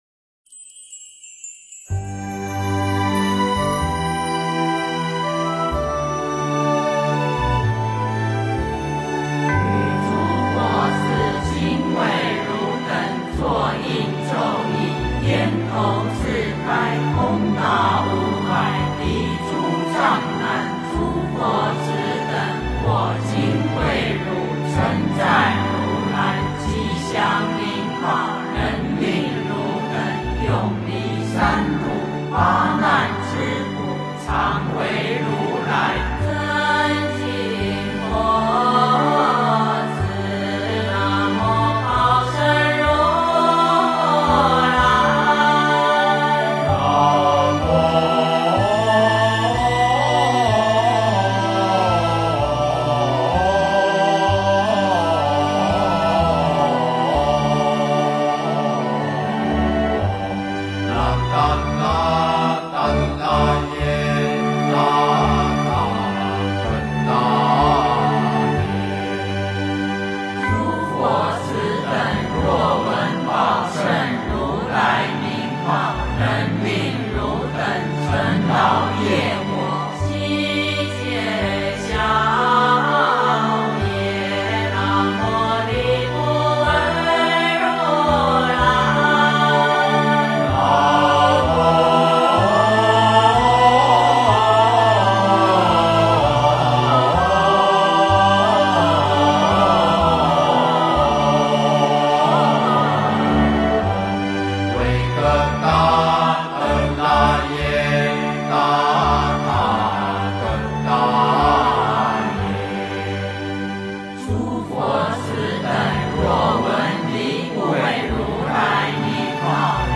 七如来礼赞 诵经 七如来礼赞--如是我闻 点我： 标签: 佛音 诵经 佛教音乐 返回列表 上一篇： 南无本师释迦牟尼佛(三称) 下一篇： 三皈依 相关文章 金光明经-赞佛品第十八 金光明经-赞佛品第十八--未知...